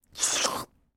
• Качество: высокое
Звук всасывания длинных спагетти человеком